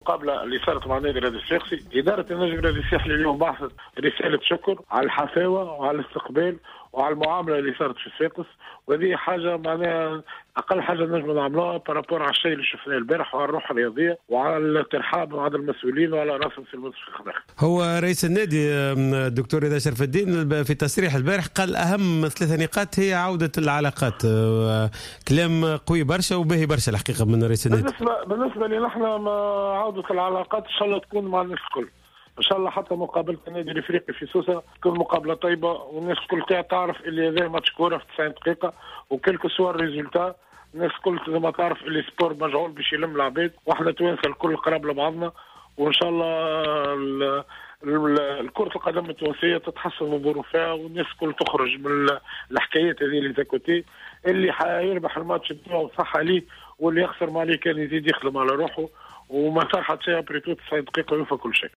خلال مداخلته في برنامج راديو سبور